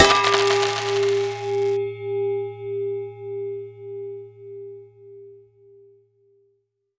item sold.wav